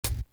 Dub Hat.wav